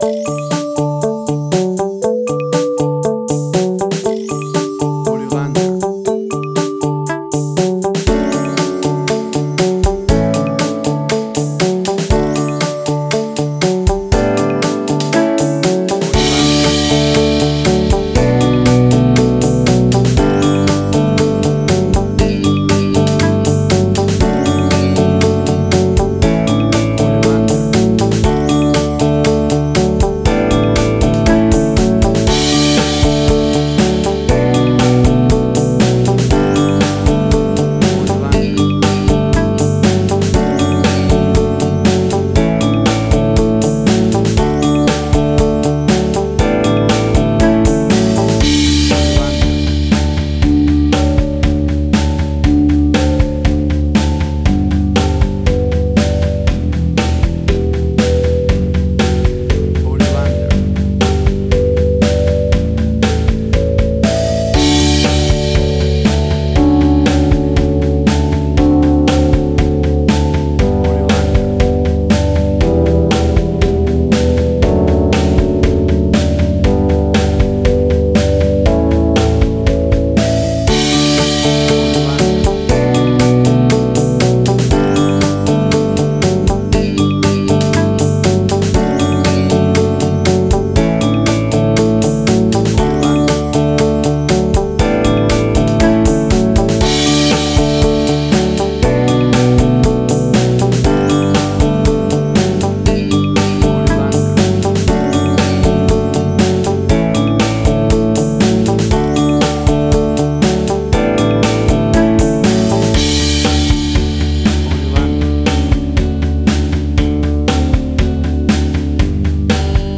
WAV Sample Rate: 16-Bit stereo, 44.1 kHz
Tempo (BPM): 120